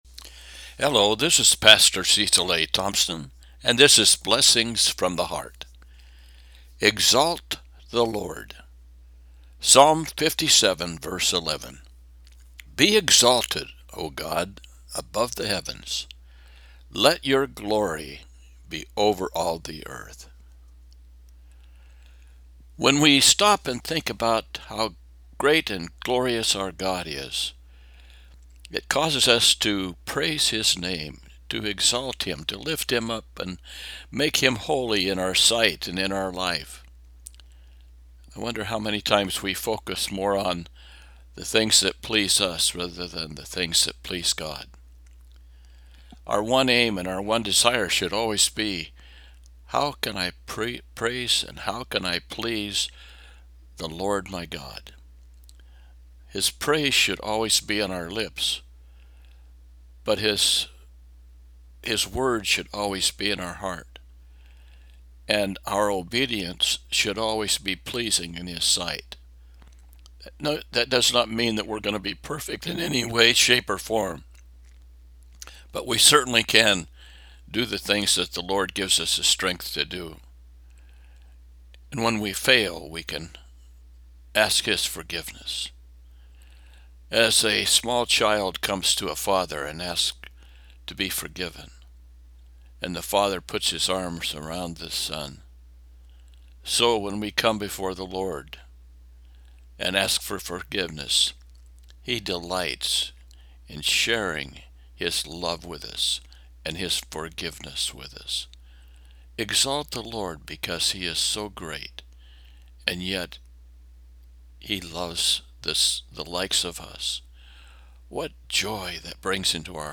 Psalm 57:11 –  Devotional